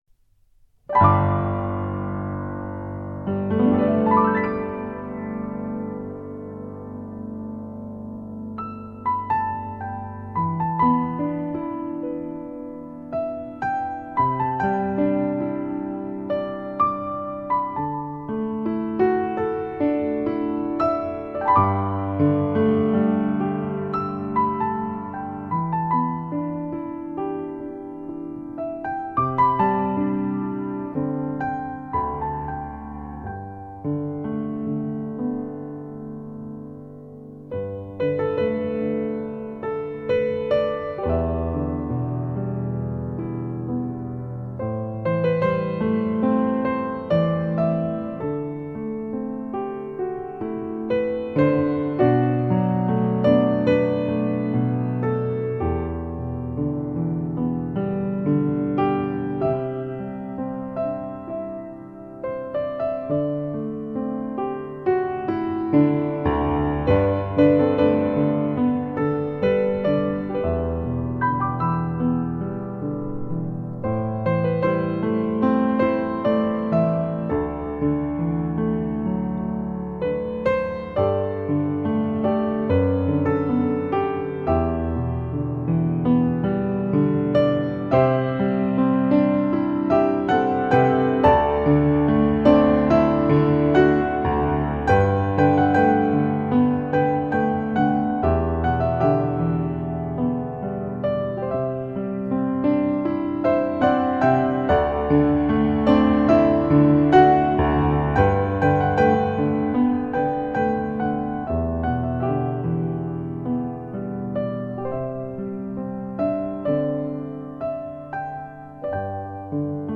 钢琴演奏
唯美悠扬，诗意空灵，真正示范级发烧三角名琴录音。
优美的钢琴旋律最易令人心情平复，也最易让人陶醉